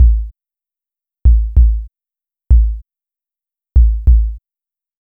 Track 14 - Kick Beat 02.wav